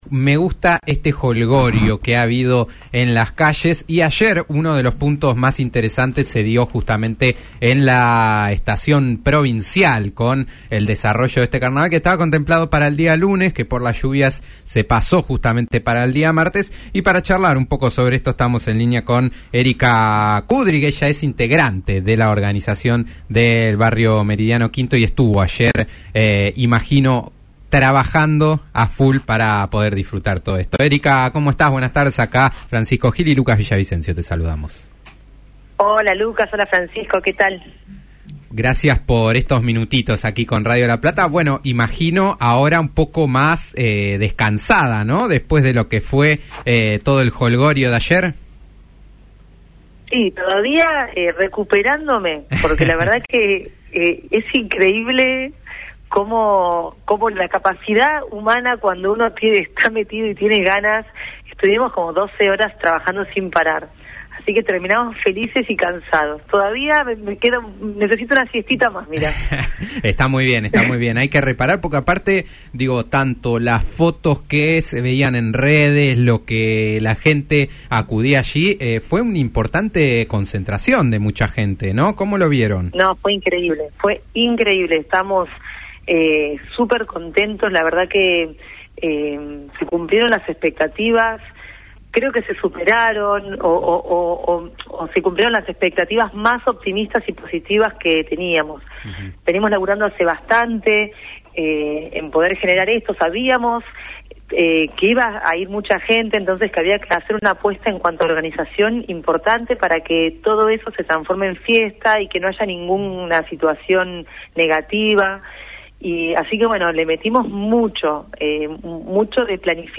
dialogó con Después del Mediodía en Radio La Plata para contar más detalles al respecto.